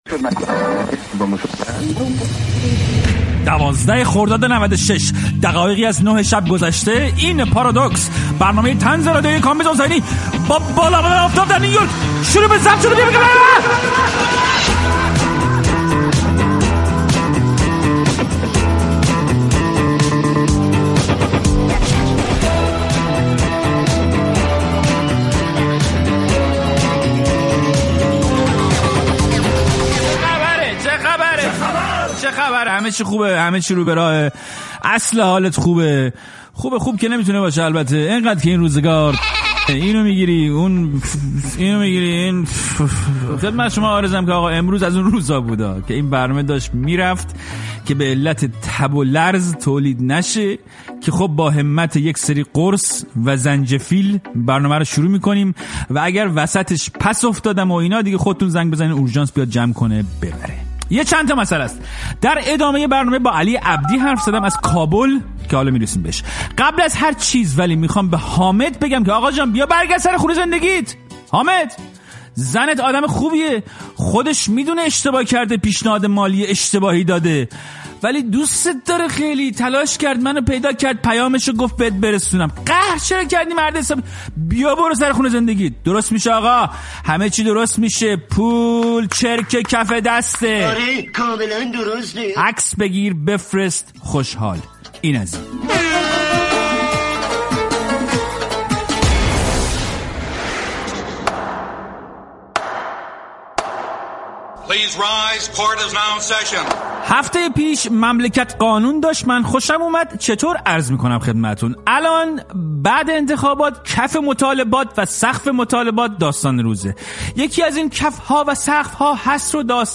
پارادوکس با کامبیز حسینی - گفت و گو